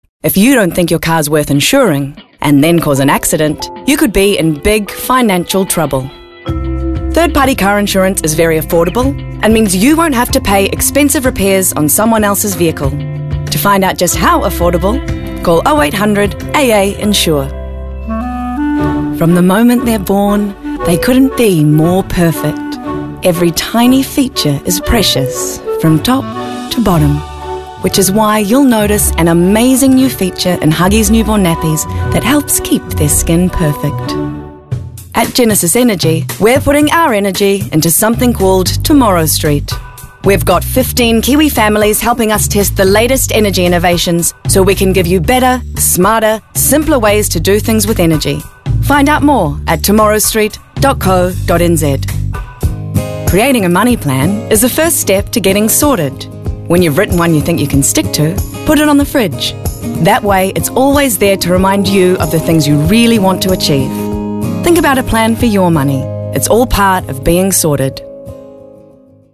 Demo
Adult
a lovely rich, warm and trustworthy tone to her voice
new zealand | natural